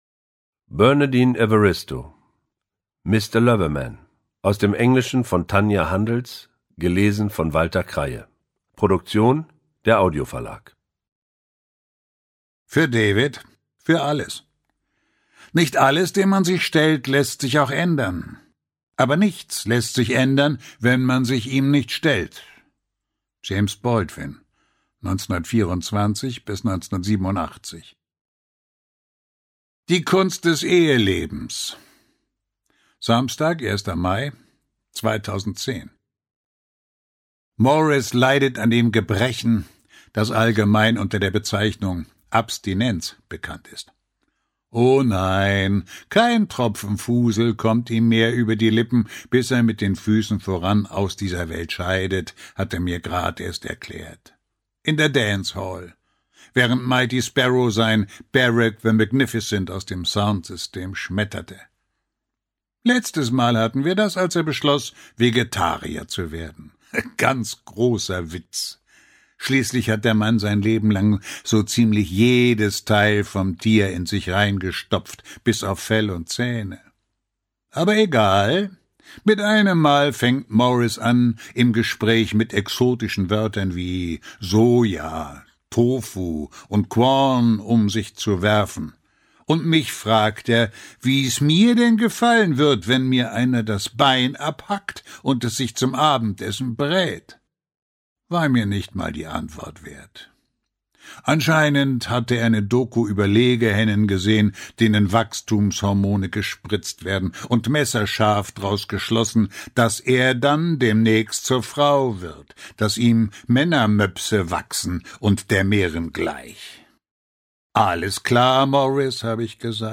Ungekürzte Lesung mit Walter Kreye (2 mp3-CDs)
Walter Kreye (Sprecher)